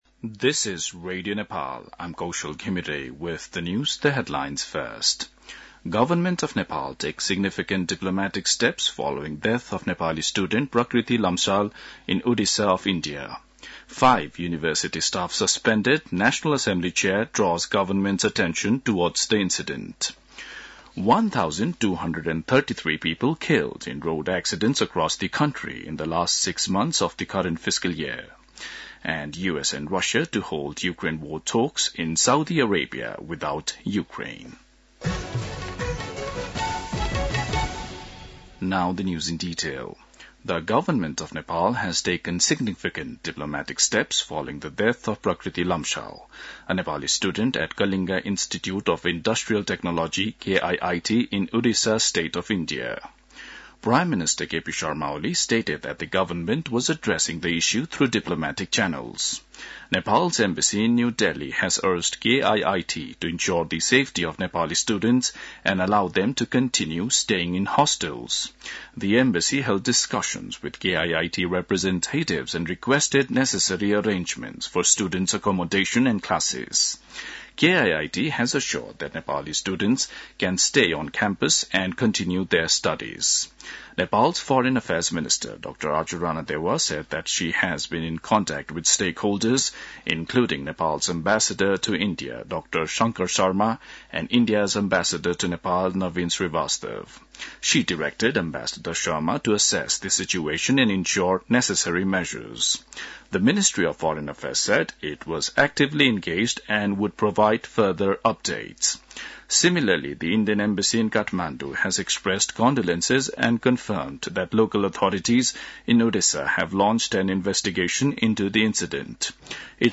दिउँसो २ बजेको अङ्ग्रेजी समाचार : ७ फागुन , २०८१